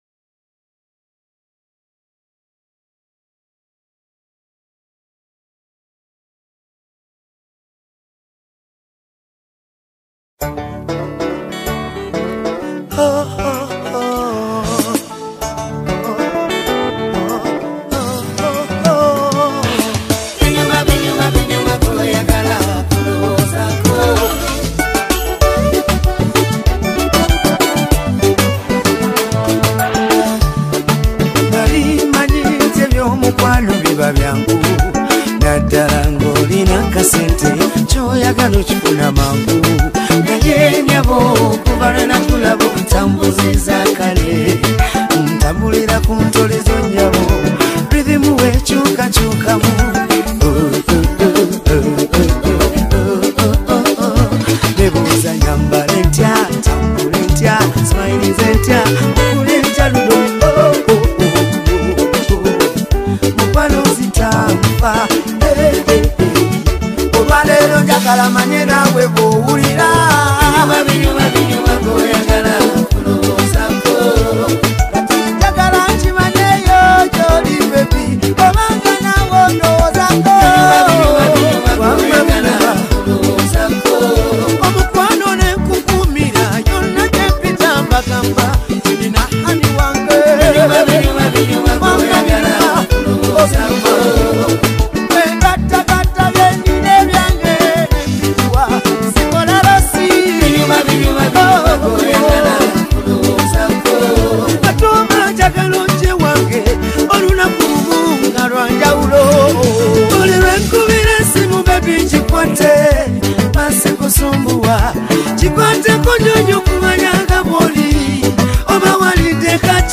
is a joyful Ugandan love song
In this uplifting track
Through smooth vocals and heartfelt lyrics